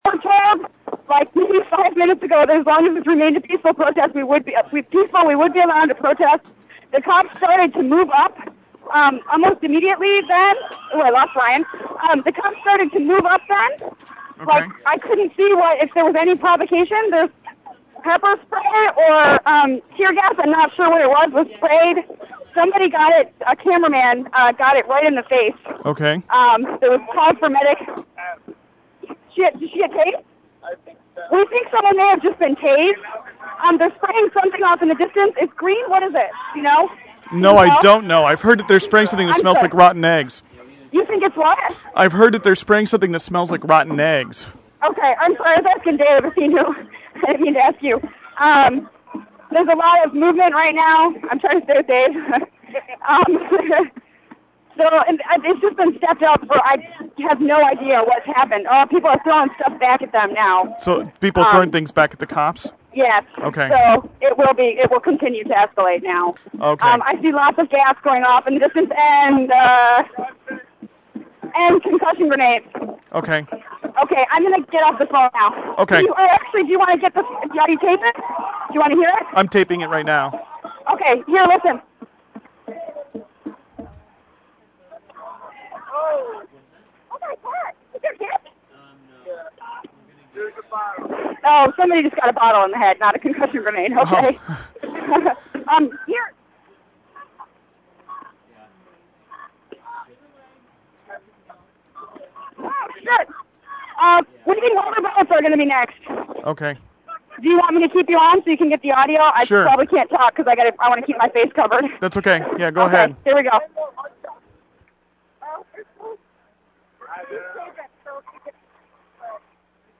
Lastest Audio From The Streets Of Miami: Police Advance Against Peaceful Protestors With Gas And Concussion Grenades